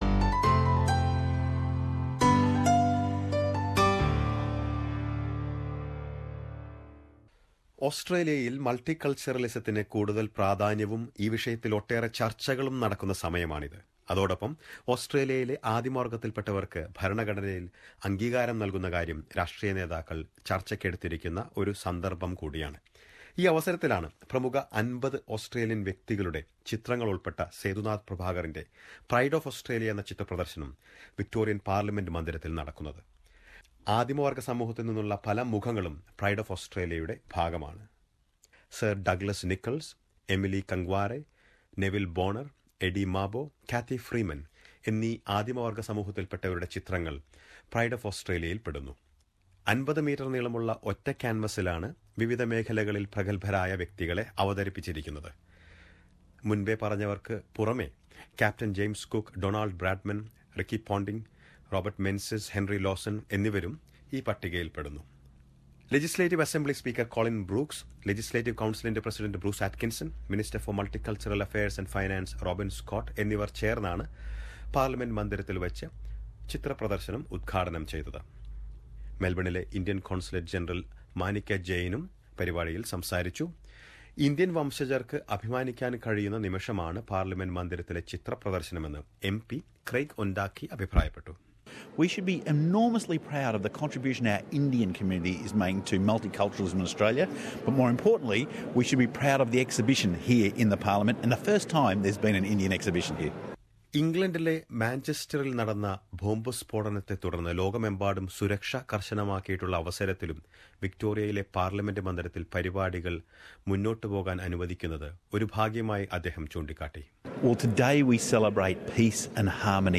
Speaker of Victorian Parliament Colin Brooks, MP, President of Upper House Bruce Atkinson, Consul General of India Manika Jain were among the dignitaries on the occasion. Listen to he report in Malayalam in the above link.